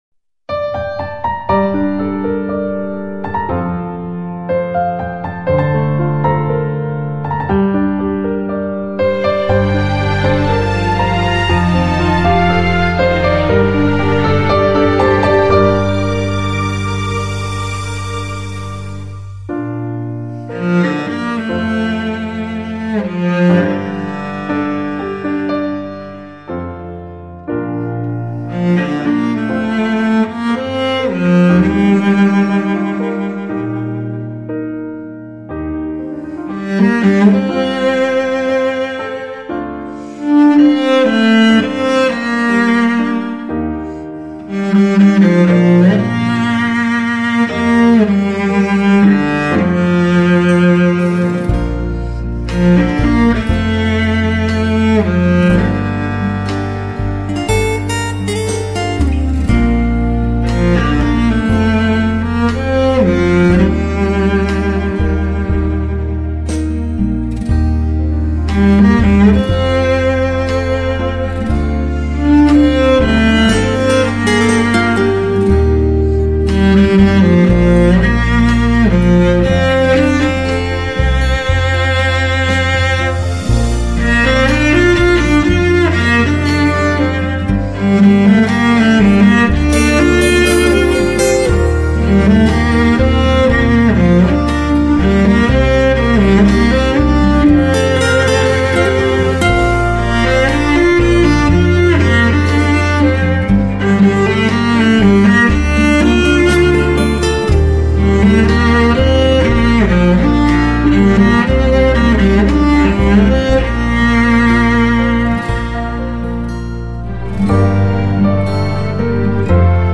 大提琴
既古典又现代的音乐风格， 柔软甜美的音质兼容散发着温暖金黄的光泽，
厚实又有韵味的音乐，栩栩如生的结像透明感， 解析力与层次感都清楚呈现，
就算少乐器依然有大气势，音乐可以很有味道又有热情。